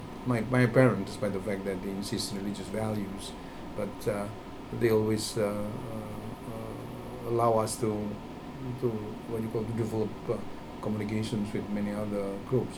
S1 = Taiwanese female S2 = Indonesian male Context: They are talking about friendship with other ethnic groups, such as Chinese people.
Discussion: These words are spoken quite fast and not very loudly, which is why S1 was unable to decipher them.